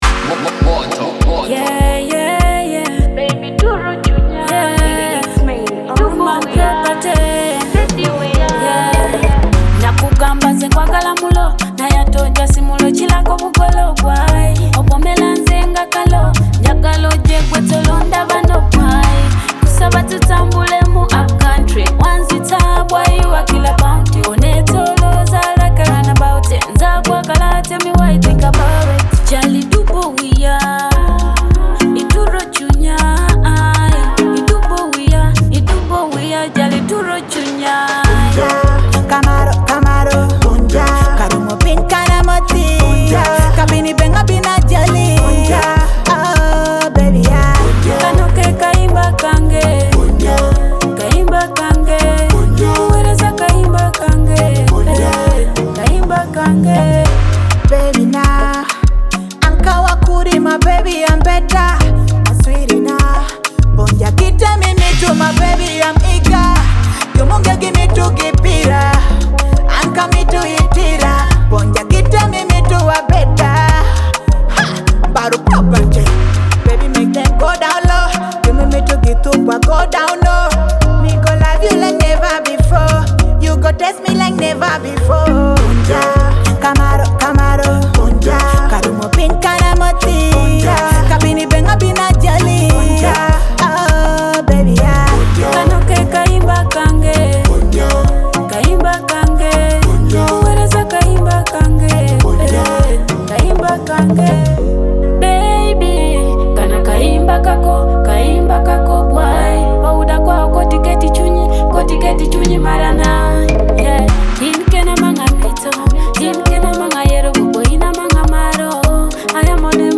your premier destination for African music!